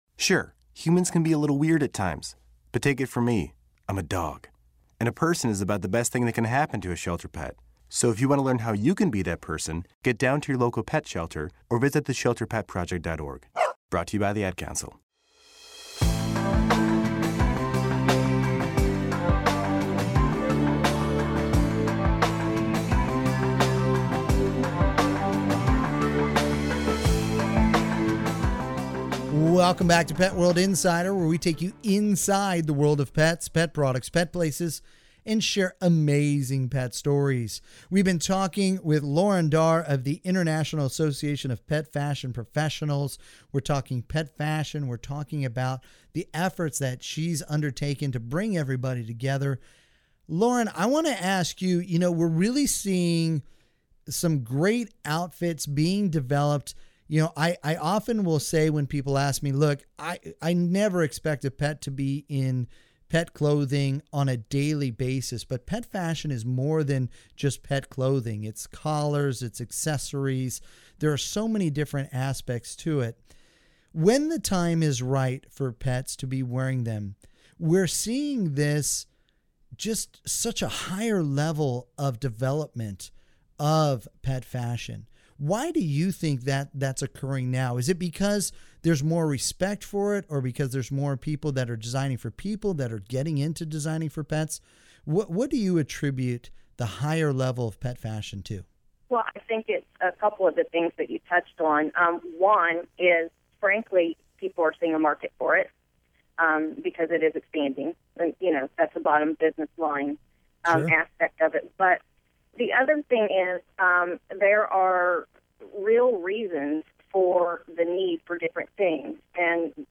Enjoy this Pet World Radio Segment in case a station near you does not currently carry Pet World Radio on the EMB or CRN Networks!